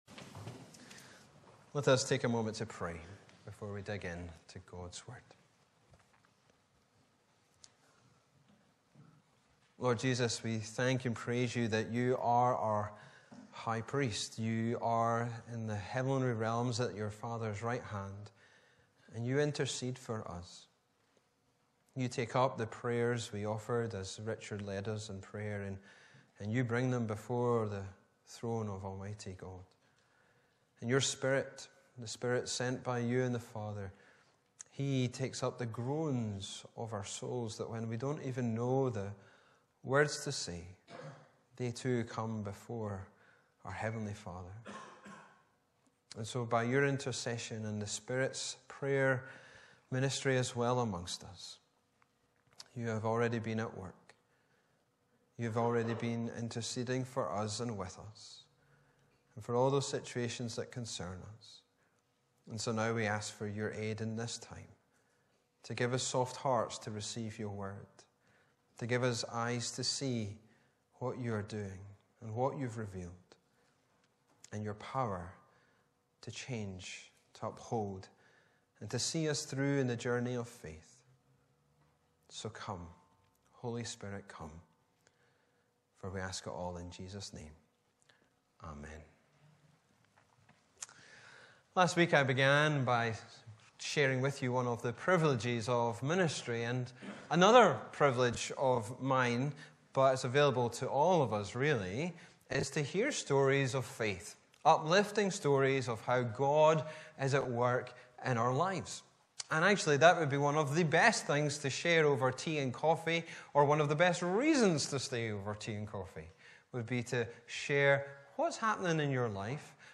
Bible references: 1 Peter 4:12-19 & 5:8-11 Location: Brightons Parish Church